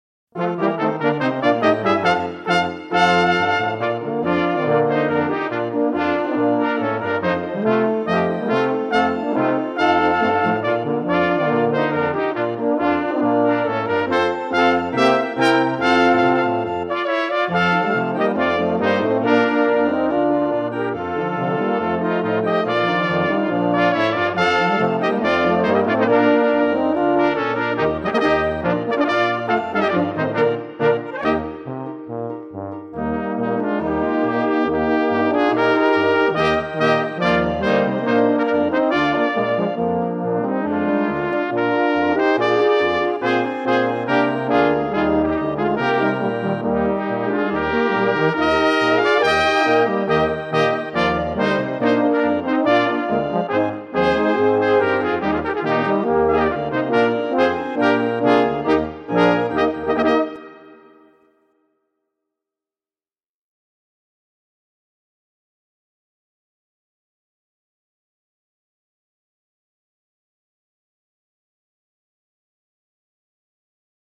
Catégorie Musique d'ensemble
Sous-catégorie Formation folklorique mixte
Instrumentation Tanzlmusi